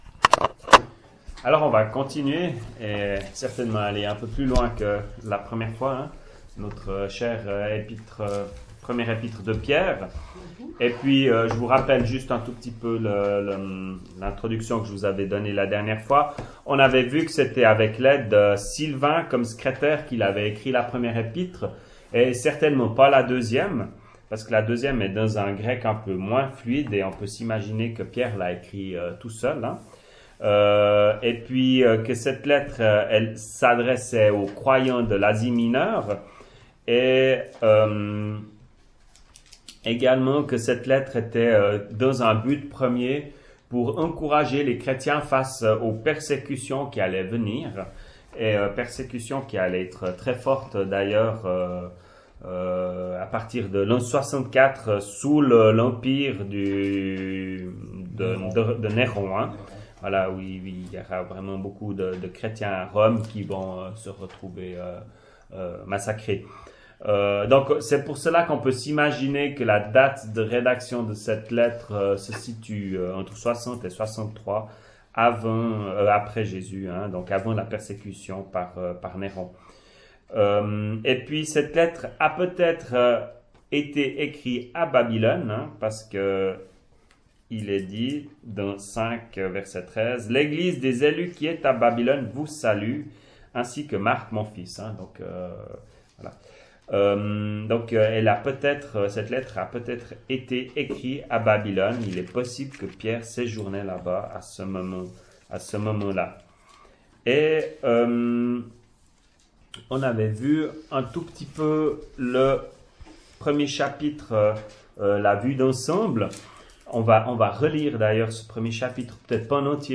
[Chapelle de l’Espoir] - Étude biblique : Première Épître de Pierre, 1ère et 2ème partie